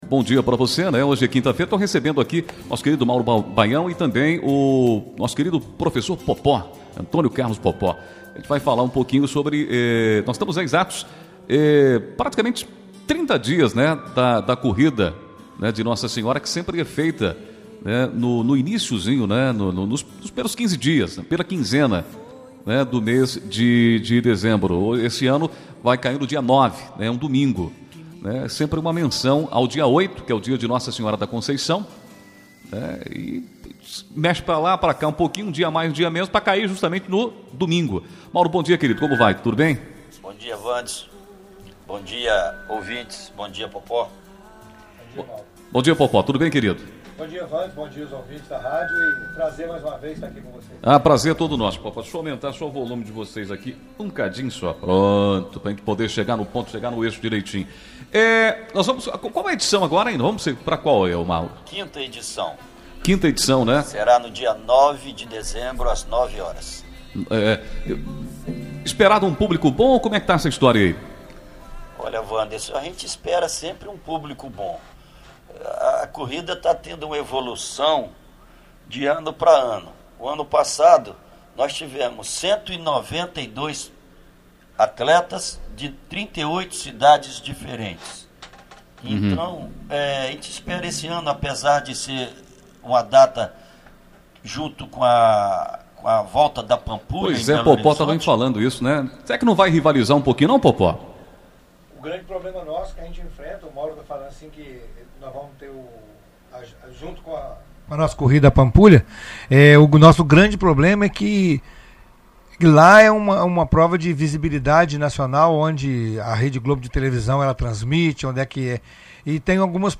9 novembro, 2018 ENTREVISTAS, NATIVIDADE AGORA
ENTREVISTA-CORRIDA.mp3